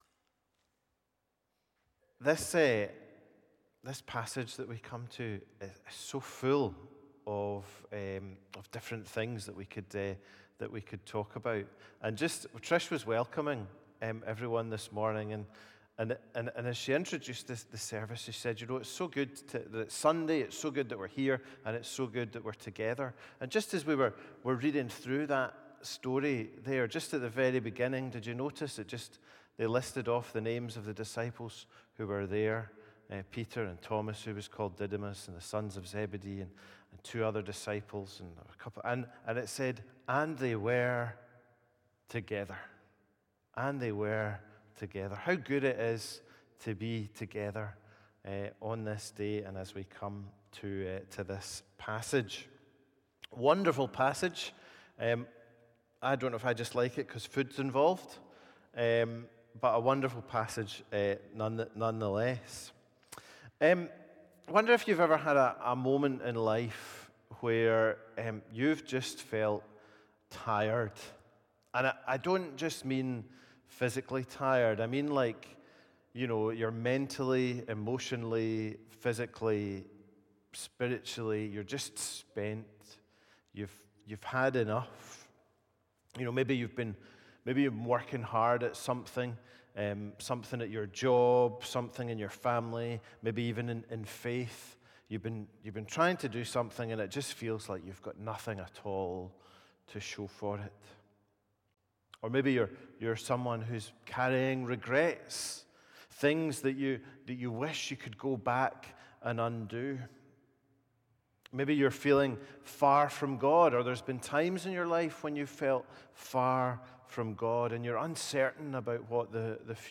A Sermon from the series "Easter 2025."